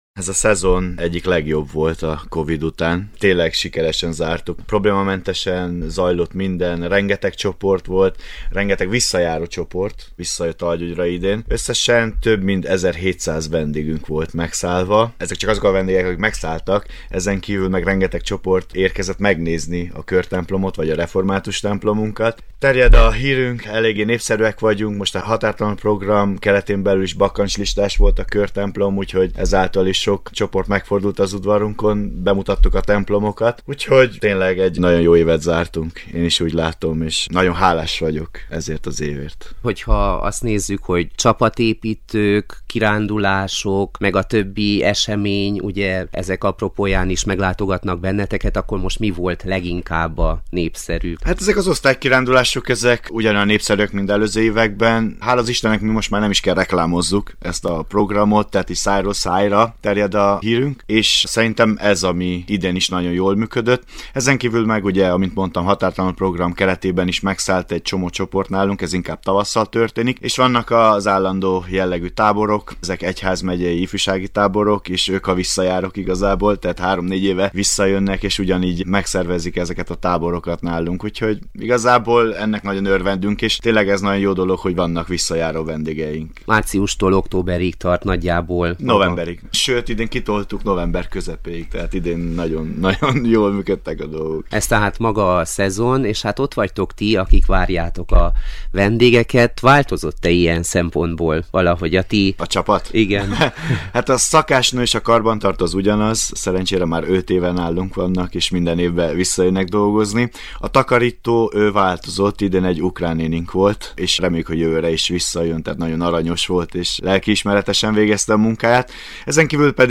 Kolozsvári látogatása során a hétvégén a Kolozsvári Rádió Donát úti stúdióját is meglátogatta, ahol az elmúlt időszak megvalósításai mellett a következő hetek, hónapok terveiről is beszélgettünk, így a december 7-én, advent második vasárnapján esedékes Csillagszóró rendezvényükről is, amelyet hagyományteremtő szándékkal hoznak tető alá, a karácsonyra való hangolódás jegyében.